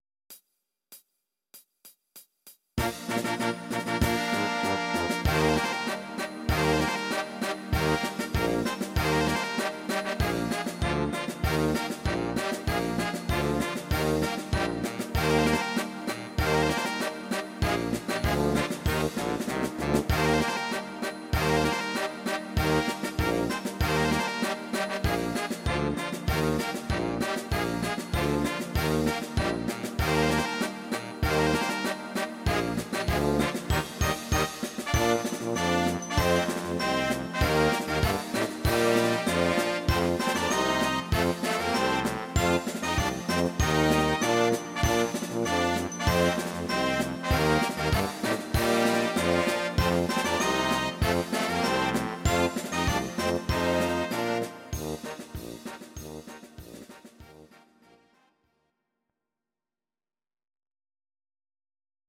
These are MP3 versions of our MIDI file catalogue.
Your-Mix: Jazz/Big Band (731)